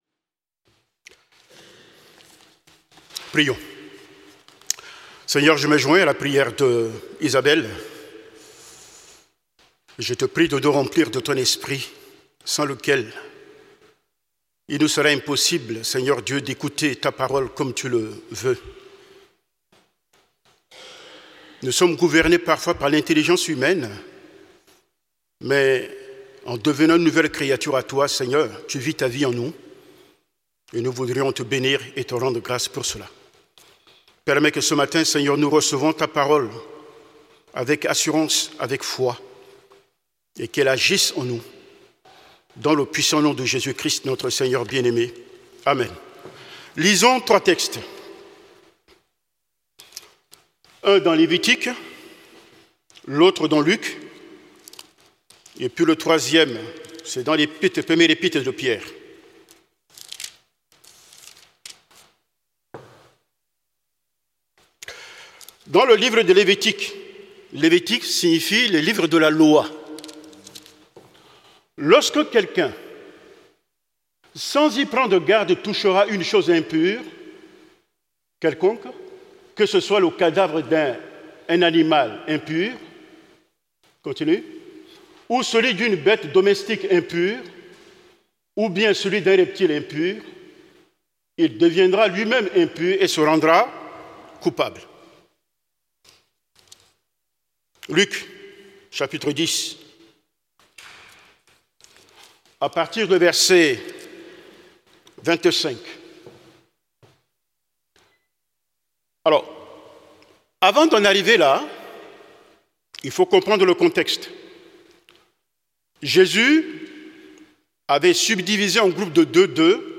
prédication du 07 décembre 2025.